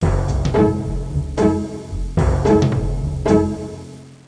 1 channel
timpani3.mp3